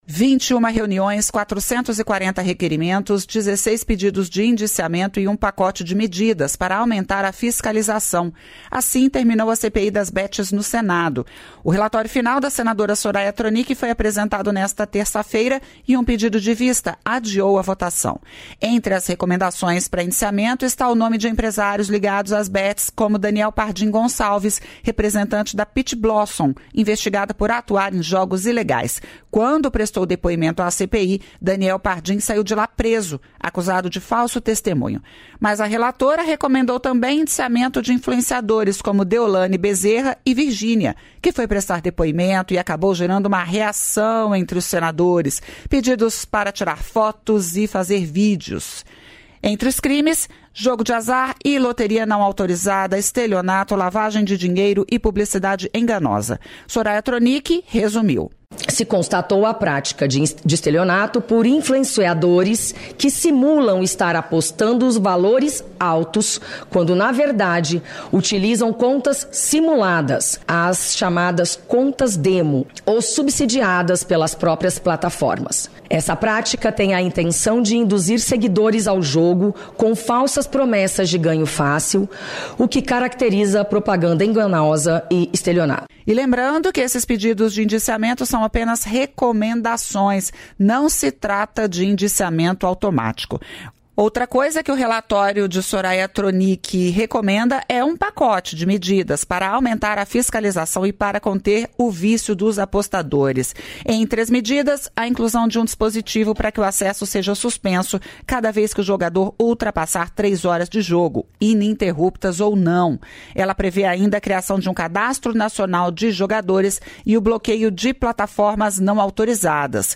O vice-presidente deu as declarações em um vídeo gravado para o encerramento do ENAEX 2021, Encontro Nacional de Comércio Exterior, fórum de amplitude nacional e de interesse de fontes internacionais, que trata de diversos assuntos que impactam o setor.